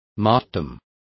Complete with pronunciation of the translation of martyrdom.